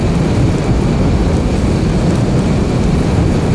flamethrower-mid-1.ogg